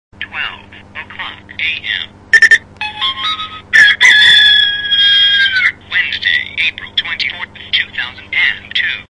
Zvuk anglicky mluvících hodinek.
Mluví anglicky, ženským hlasem.